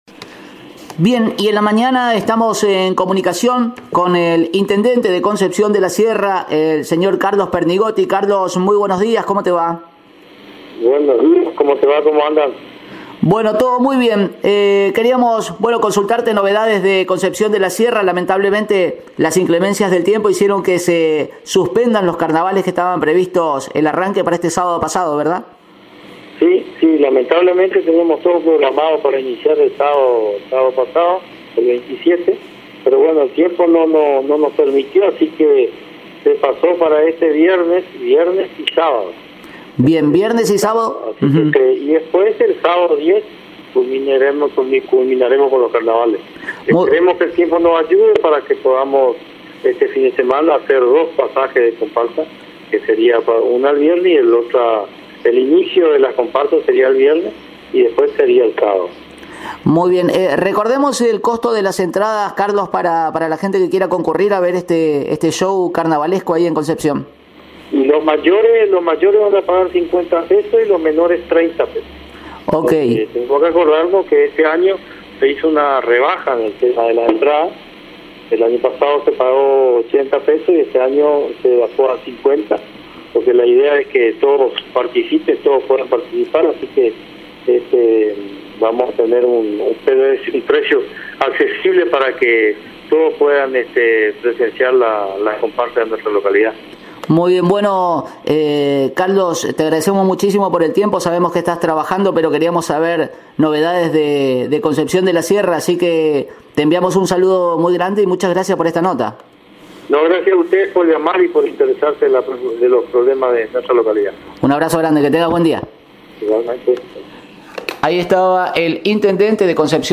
El Intendente de la localidad, Carlos Pernigotti, explicó de la postergación, por inclemencias del tiempo, de los carnavales de esa localidad.